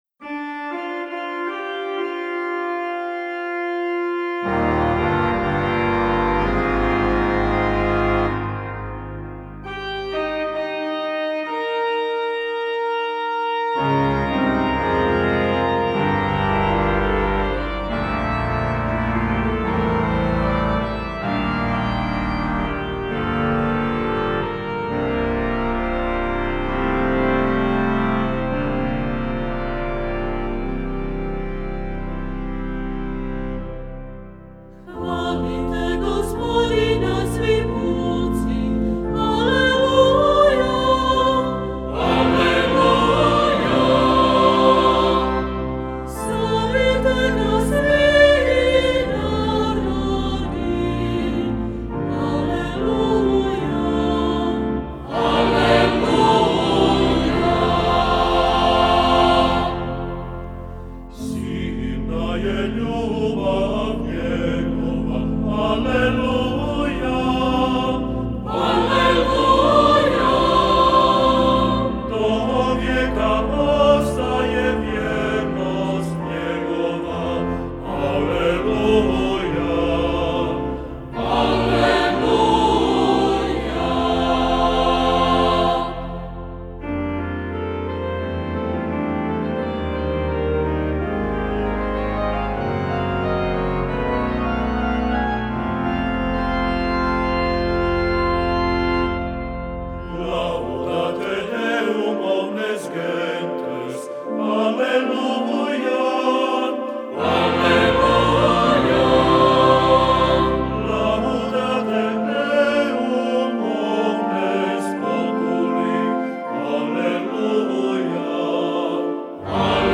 Za koji sastav Pučko pjevanje, Mješoviti zbor, Solisti
Vrsta skladbe Himan